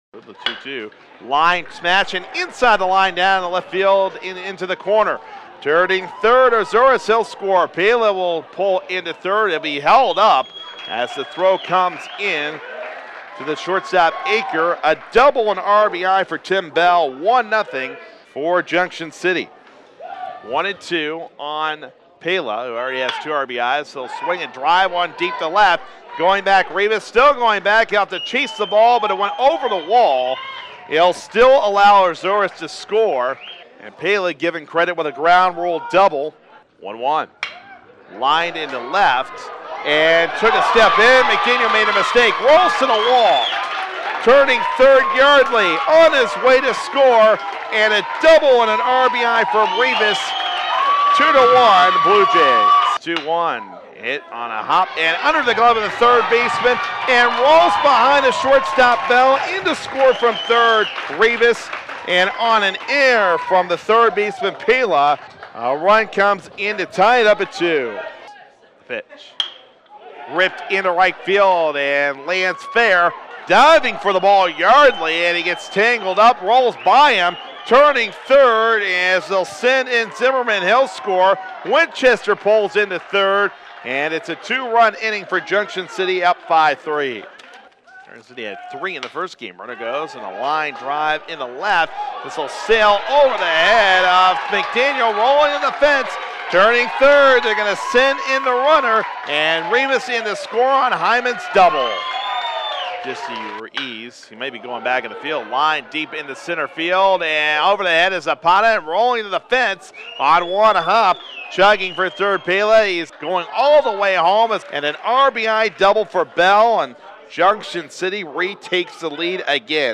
Game Two Highlights
garden-junction-highlights-two.mp3